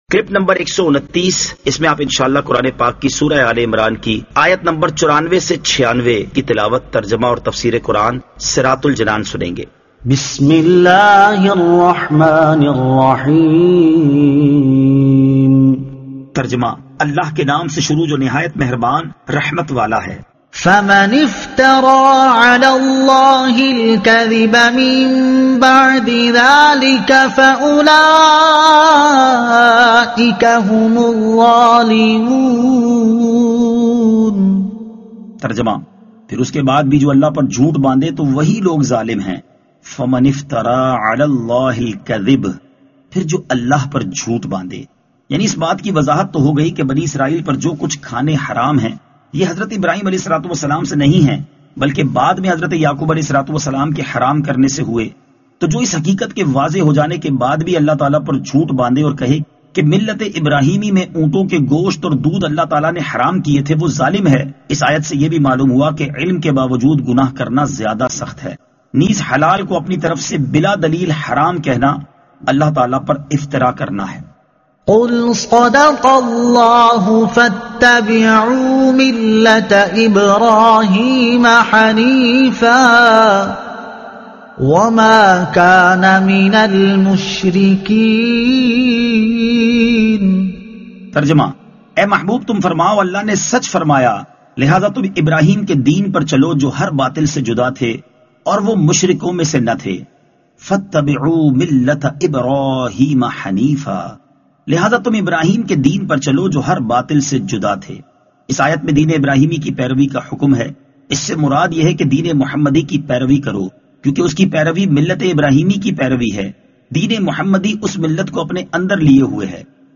Surah Aal-e-Imran Ayat 94 To 96 Tilawat , Tarjuma , Tafseer